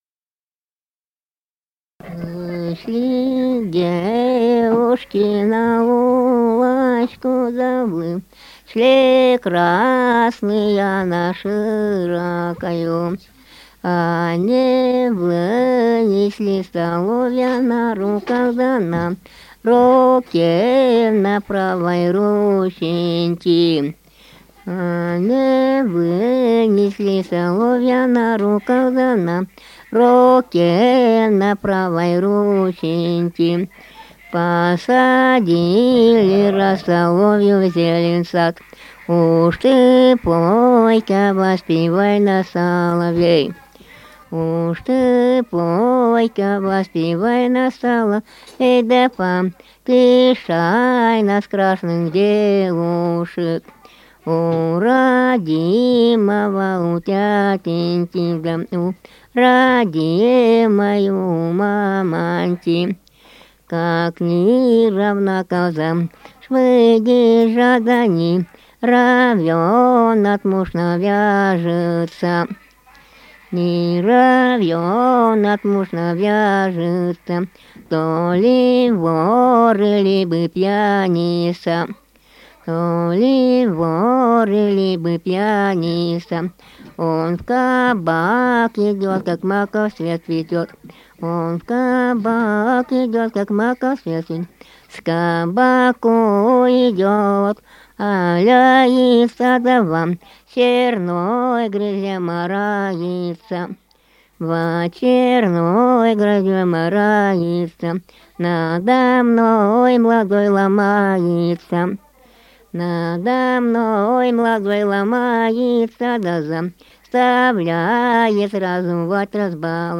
Республика Алтай, Усть-Коксинский район, с. Верхний Уймон, июнь 1980.